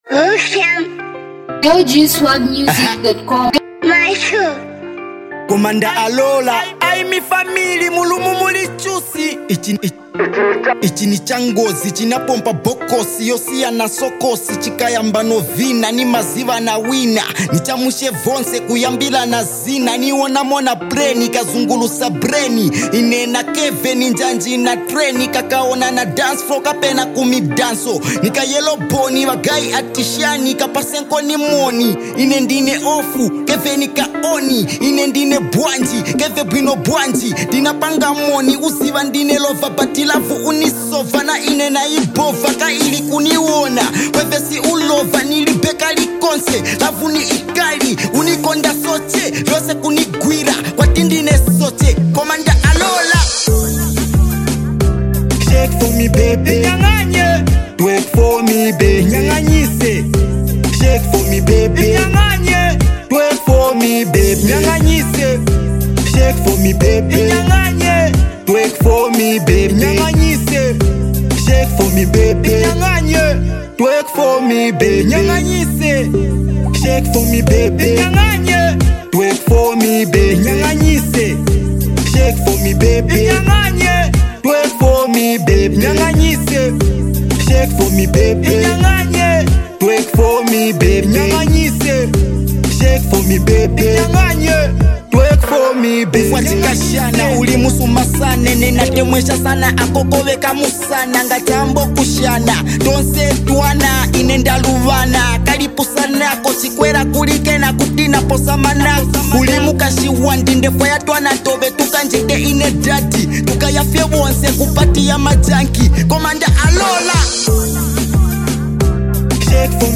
a rapper
This is a club banger that you need to vibe on.